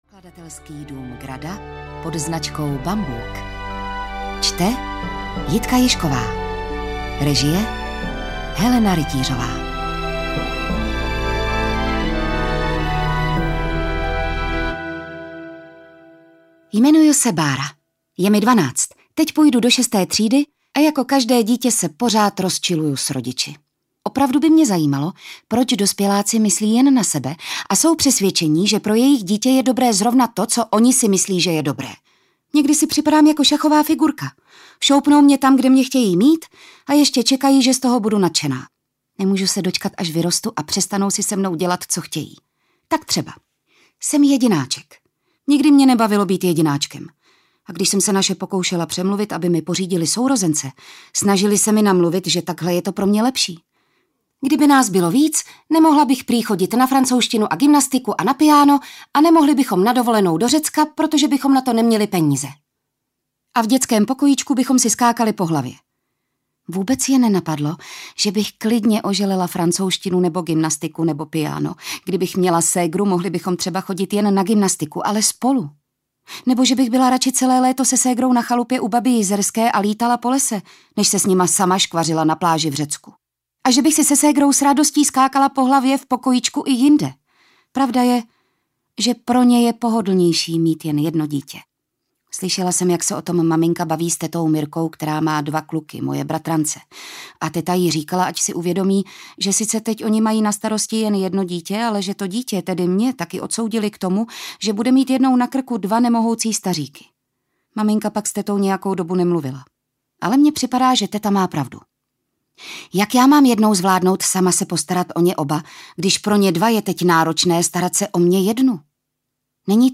Husité audiokniha
Ukázka z knihy